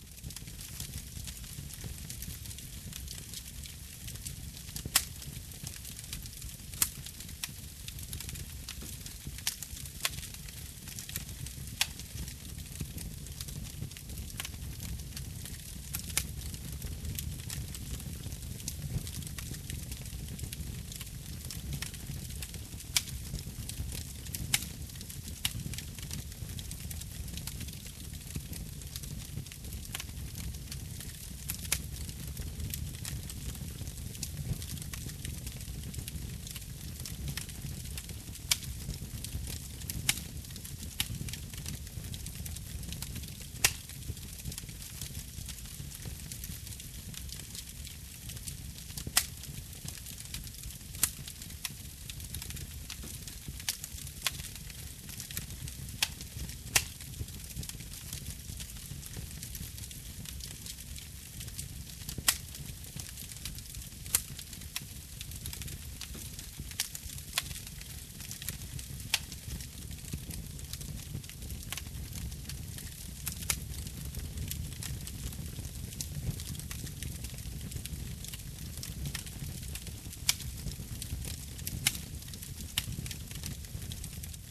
zvuk-kamina.ogg